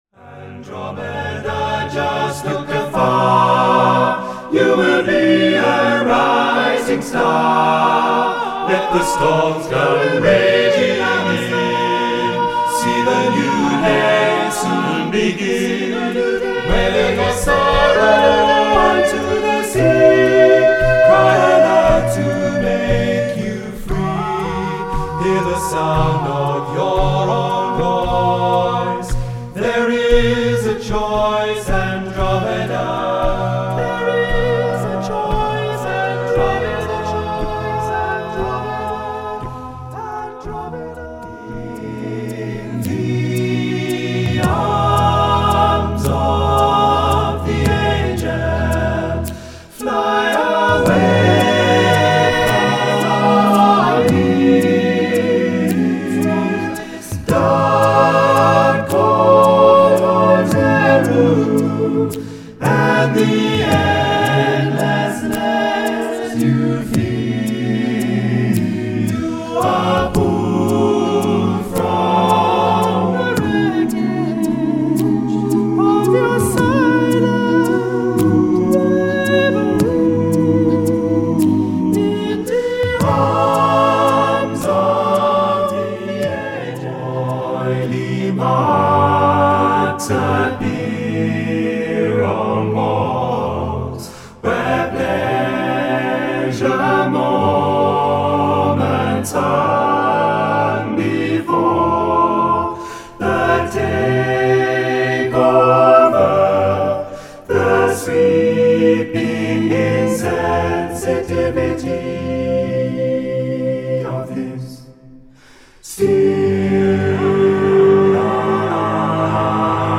Recueil pour Chant/vocal/choeur